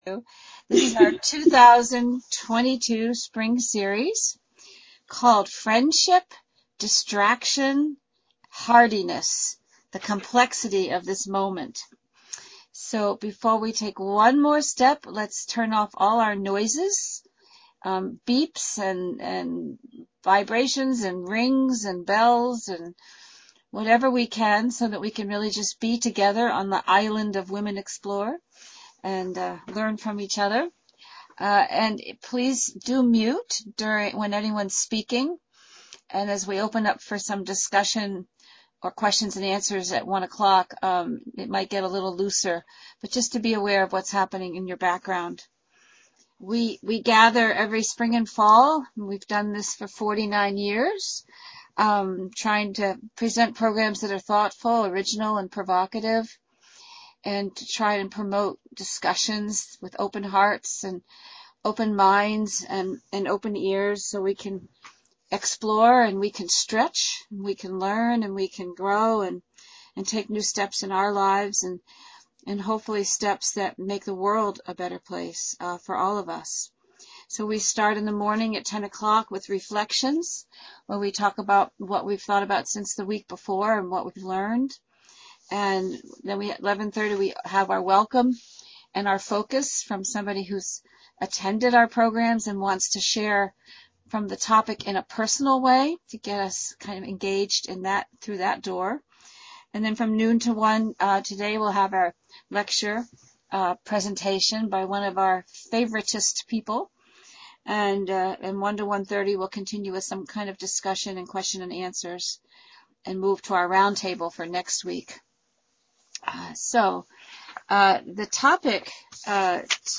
Focus talk